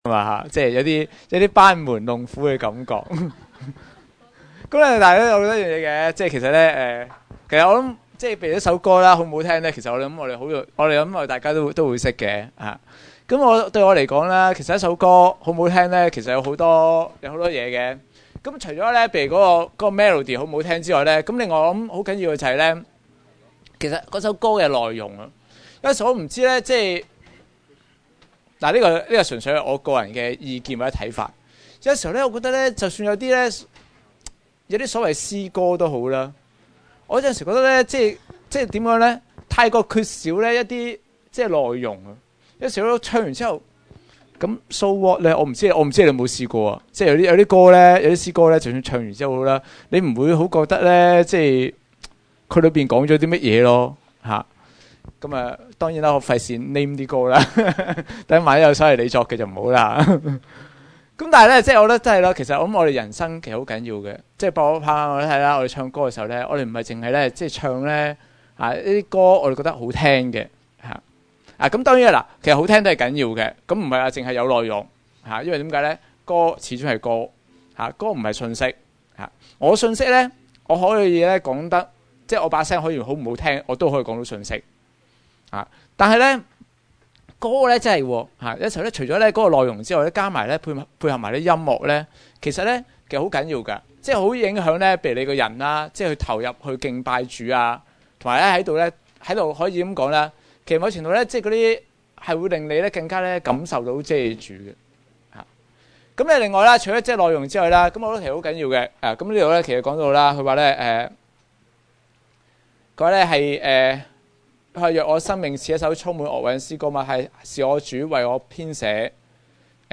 SINGLE SERMONS ARCHIVE PODCASTS 2008 | 單篇信息 PODCASTS 2008